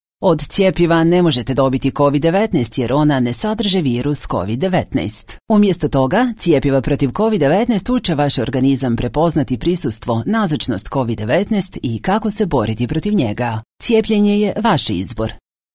Serbian female voice talent